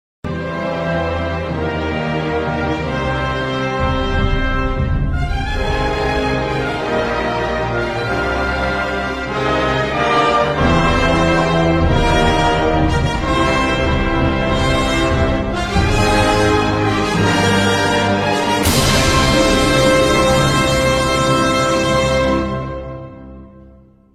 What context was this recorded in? themesong made in an audiomixing program